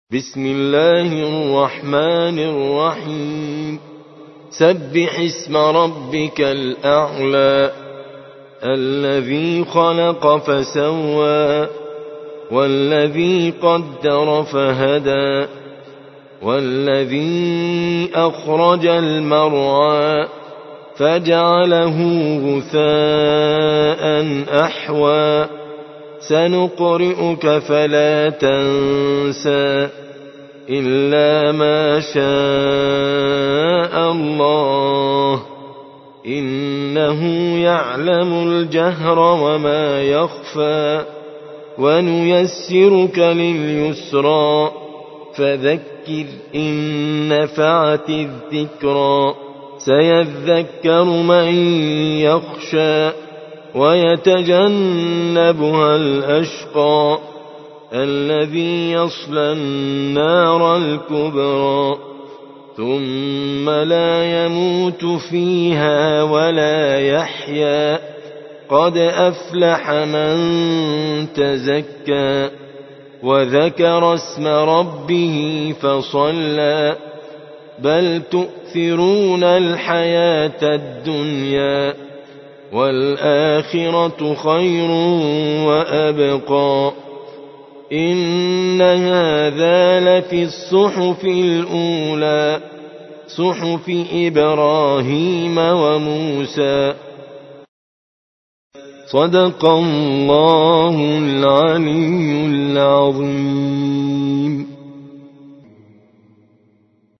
87. سورة الأعلى / القارئ